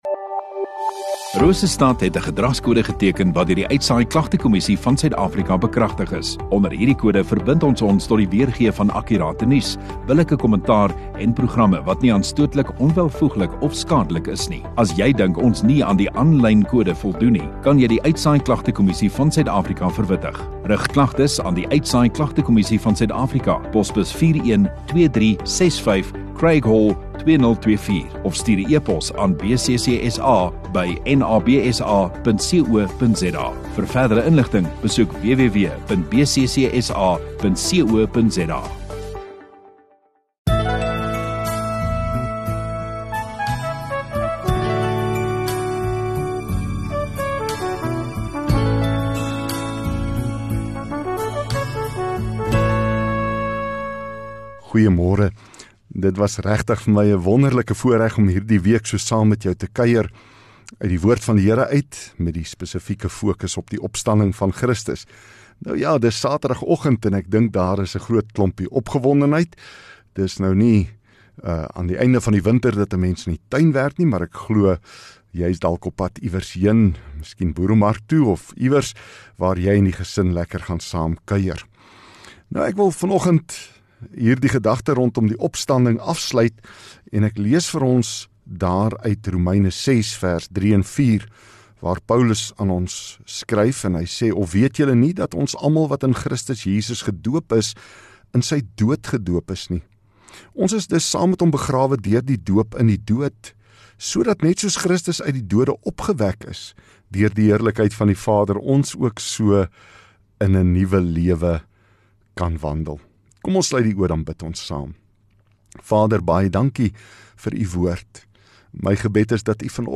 27 Apr Saterdag Oggenddiens